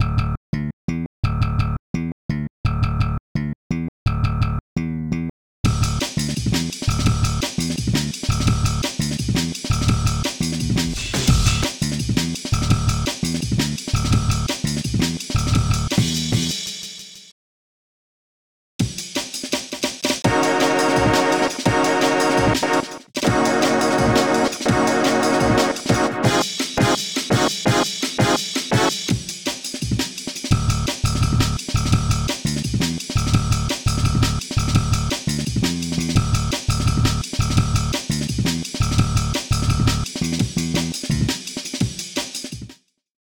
added more to that break core thing i did yesterday
sorry about the "cuts" cant do shit about em i guess